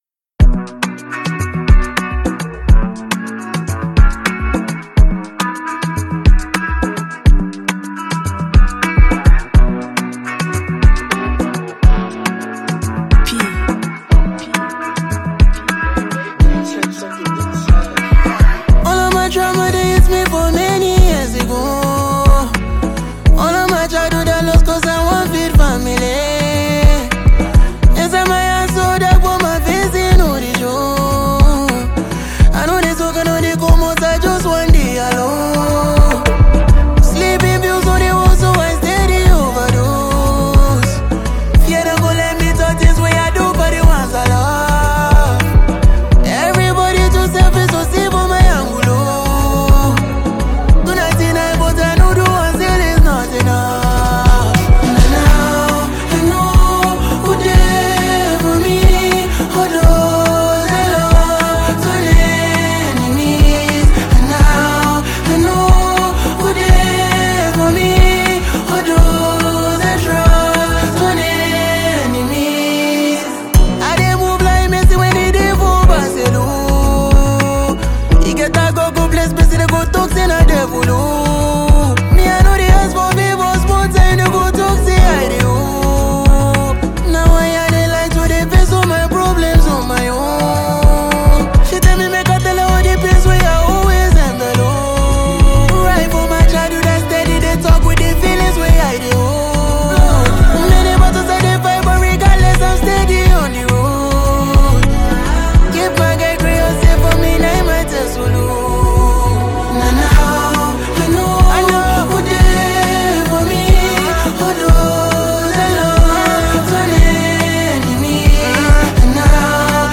a top-tier Nigerian afrobeat singer-songwriter